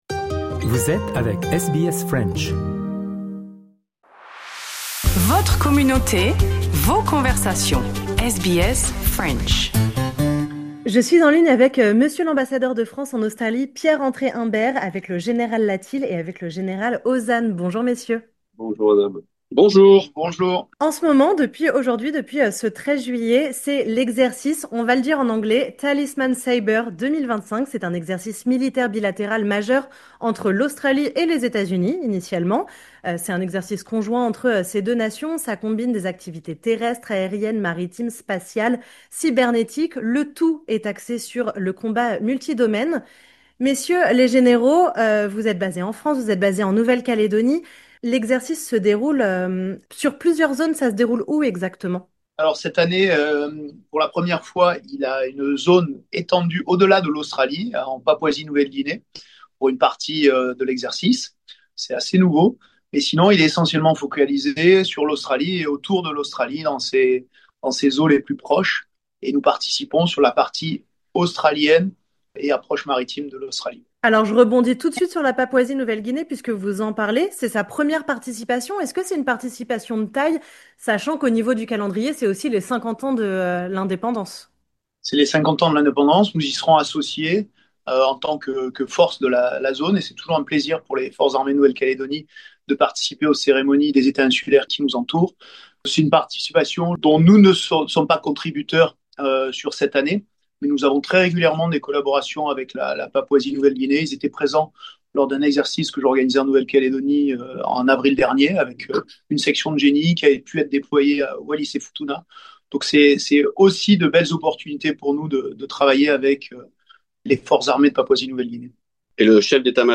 À l’occasion de l’exercice militaire Talisman Sabre 2025, la France réaffirme son engagement dans la région Indo-Pacifique aux côtés de ses partenaires internationaux. Nous avons rencontré l’ambassadeur de France en Australie, Pierre-André Imbert, ainsi que les généraux Latil et Ozanne, pour évoquer la participation française à cet événement militaire bilatéral majeur entre l'Australie et les États-Unis, un exercice conjoint qui combine des activités terrestres, aériennes, maritimes, spatiales et cybernétiques, axé sur le combat multi-domaine.